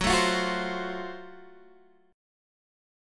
GbmM7b5 chord